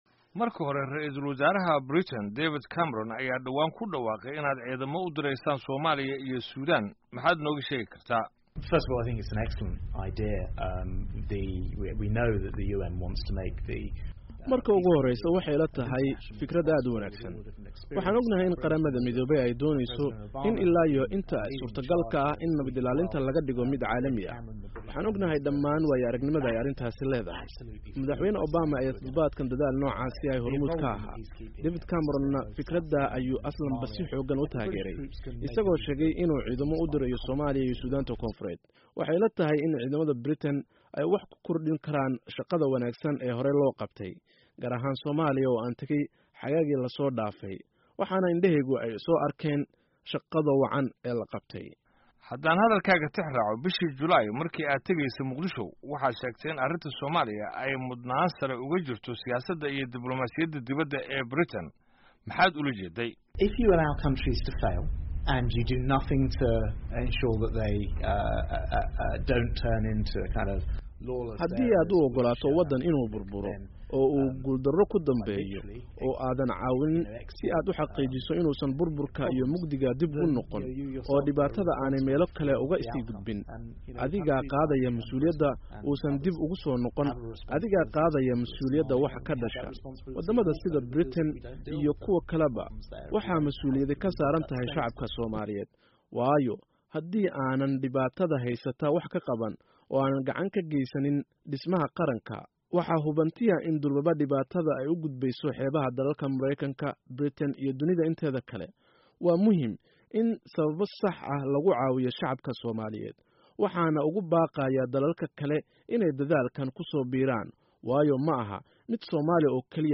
Wareysi: Grant Shaps